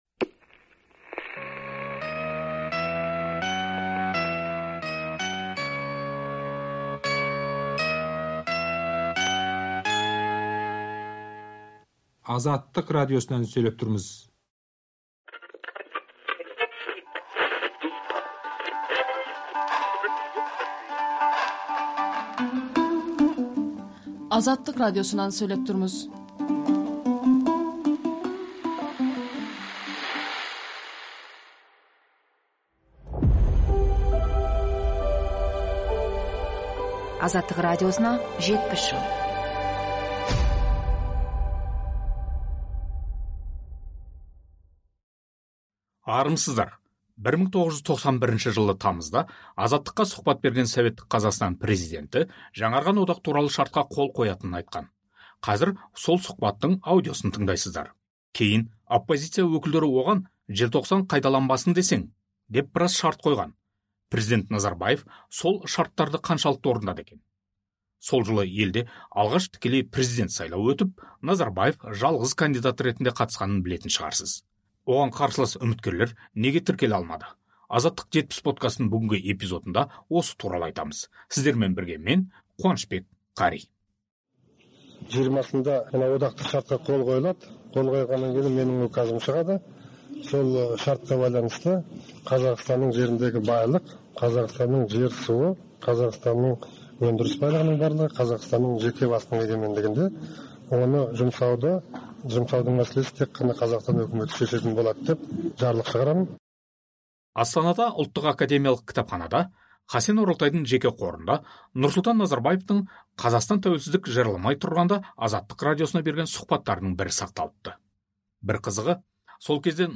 Назарбаевтың Азаттыққа сұхбаты